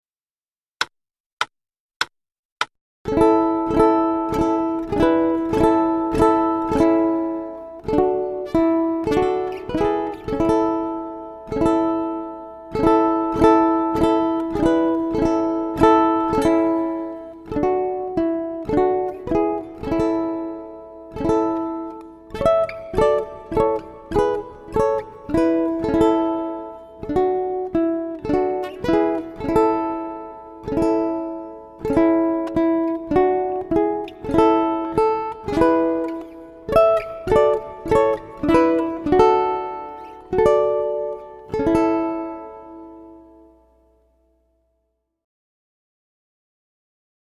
DIGITAL SHEET MUSIC - UKULELE SOLO